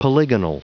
Prononciation du mot polygonal en anglais (fichier audio)
Prononciation du mot : polygonal